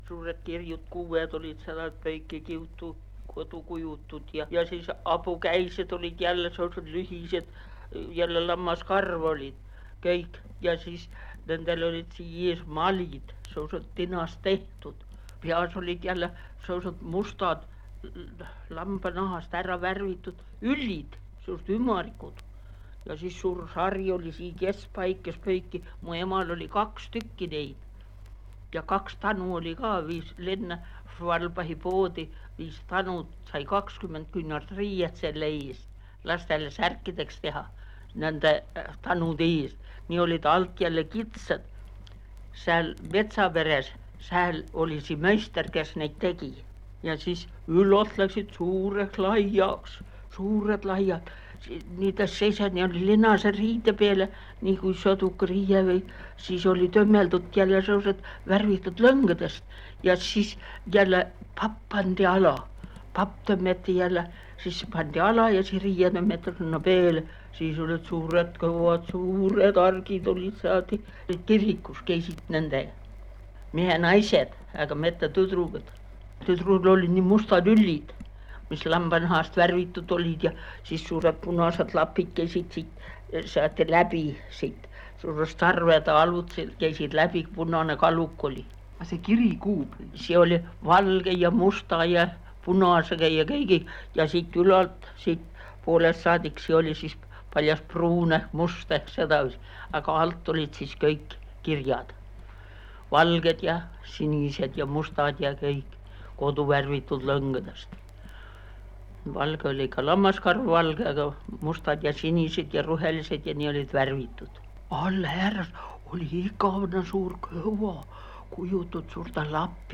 MurdekiikerSaarte murreSMustjala